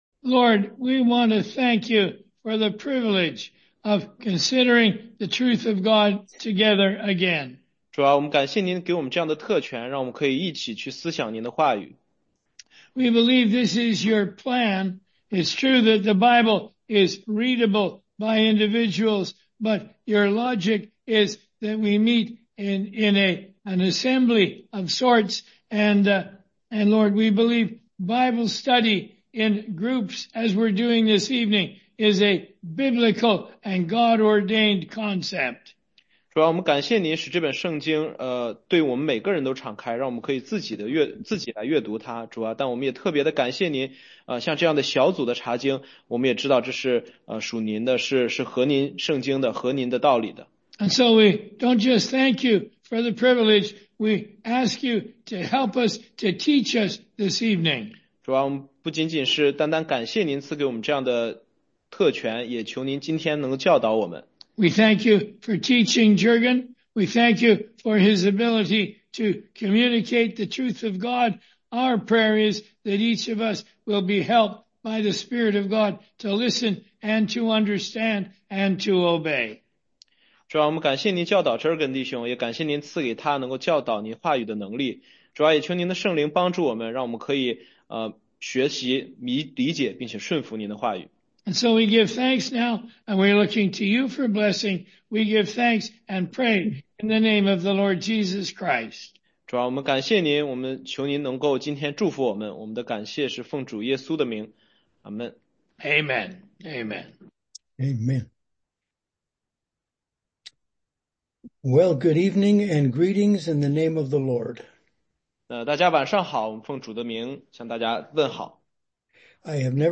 16街讲道录音